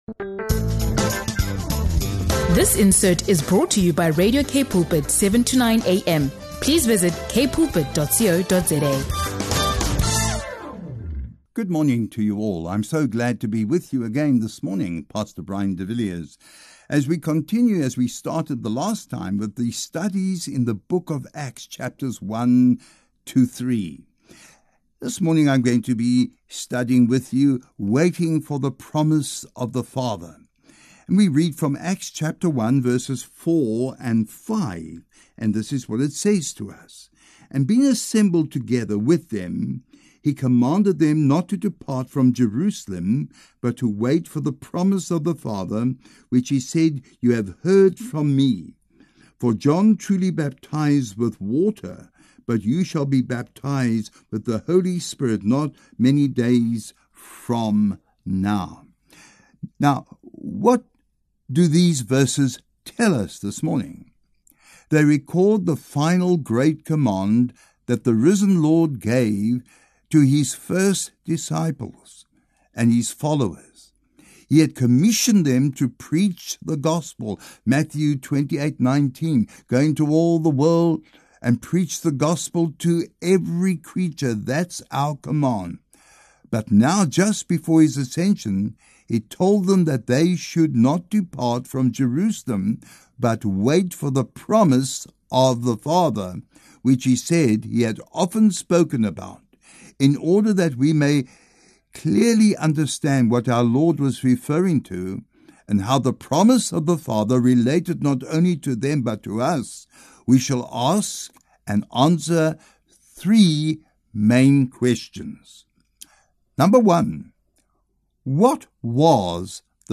In this in-depth Bible teaching from the book of Acts chapters 1–3